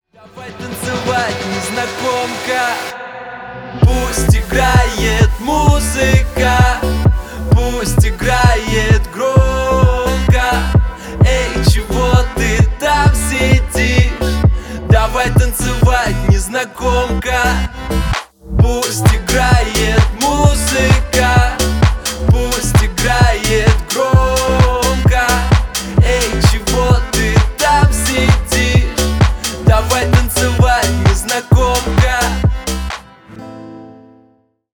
Поп Музыка
Танцевальные